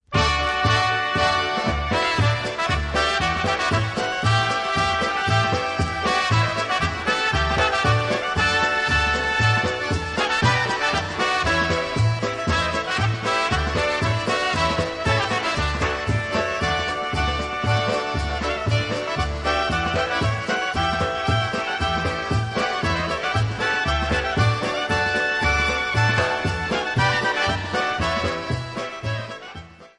Vocals & Drums
Trumpet
Clarinet & Sax
Accordion
Bass
Piano